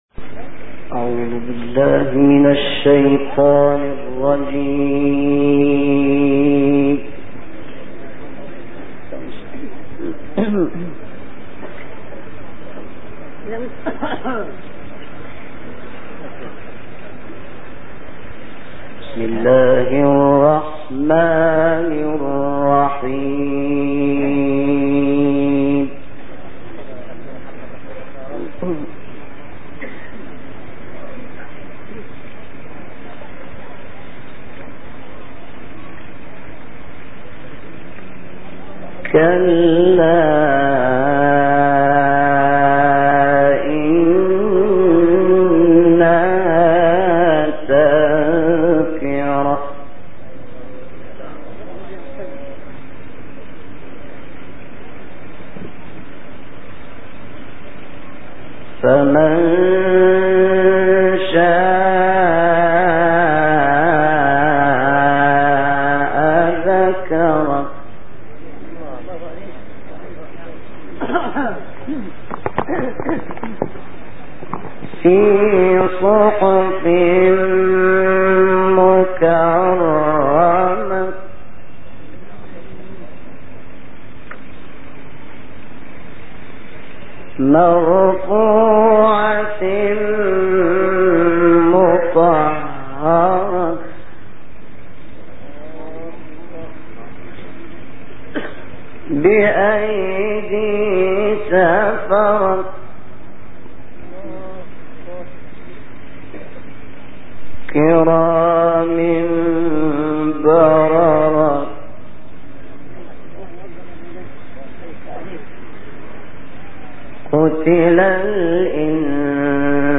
শাহাত মুহাম্মাদ আনওয়ারের সুললিত কণ্ঠে কুরআন তিলাওয়াত
শাহাত মুহাম্মাদ আনওয়ারের সুললিত কণ্ঠে বসন্তের আয়াতের সমন্বয়ে সূরা আবাসের তিলাওয়াত শুনবো।